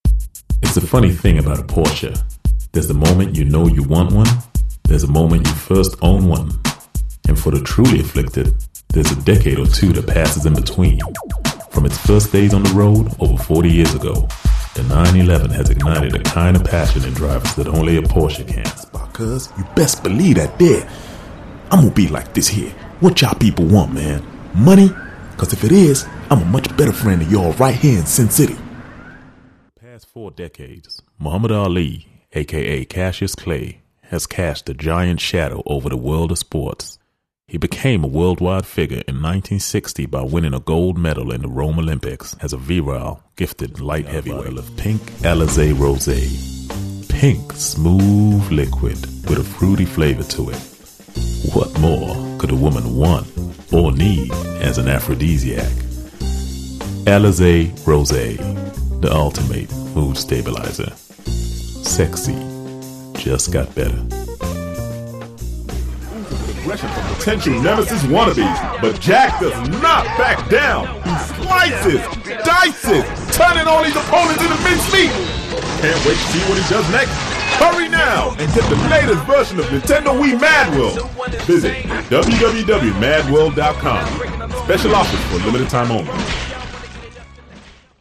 30s-40s. Male. US.